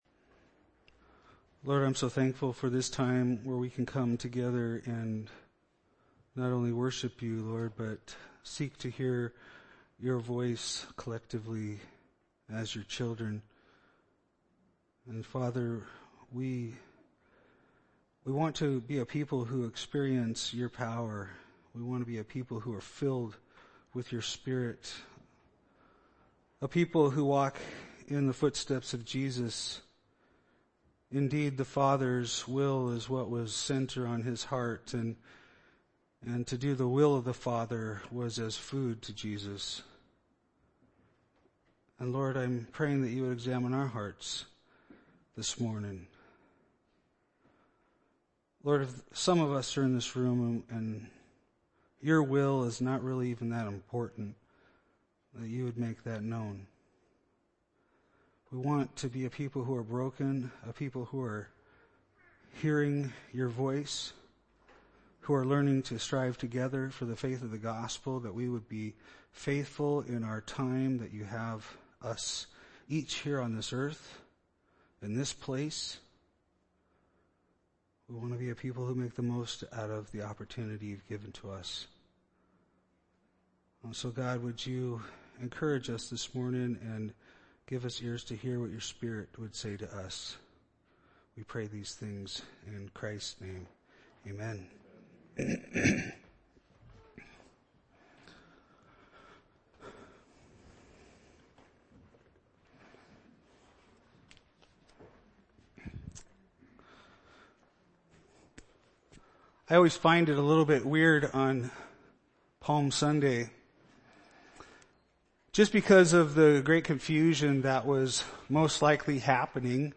This sermon blessed my spirit.